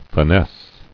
[fi·nesse]